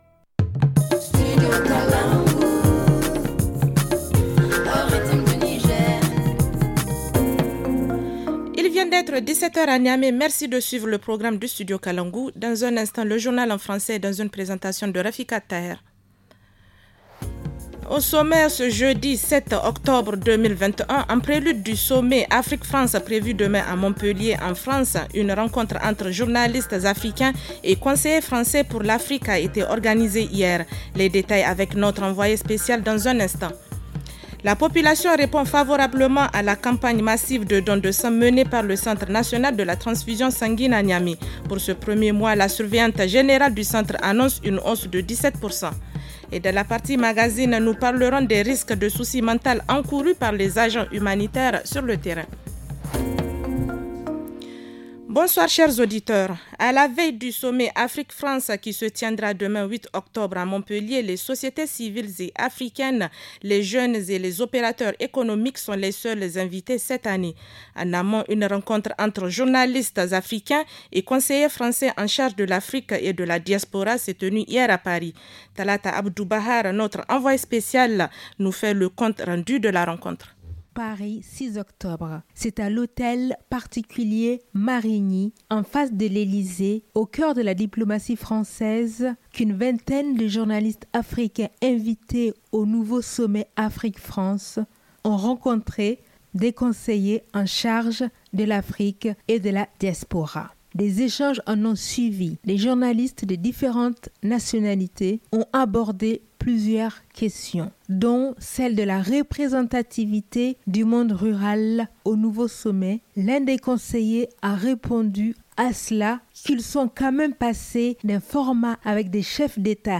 Le journal du 07 octobre 2021 - Studio Kalangou - Au rythme du Niger